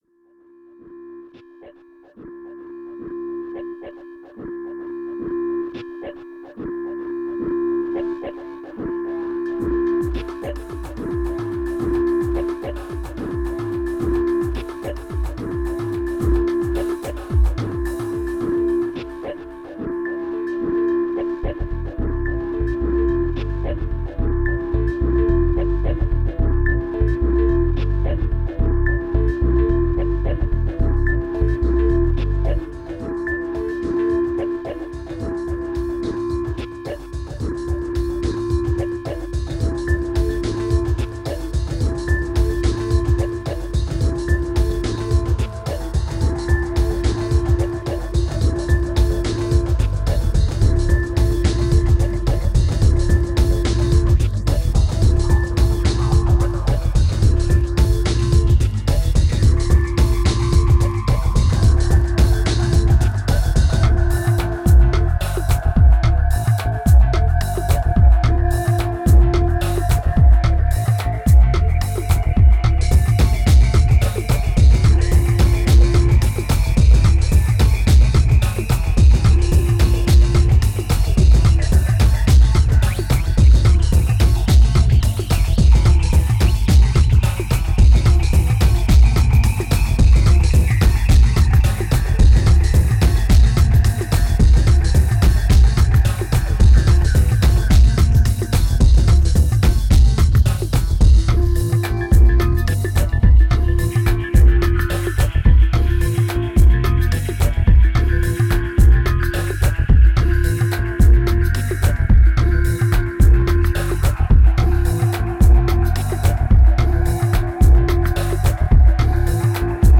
2113📈 - -14%🤔 - 109BPM🔊 - 2010-10-31📅 - -200🌟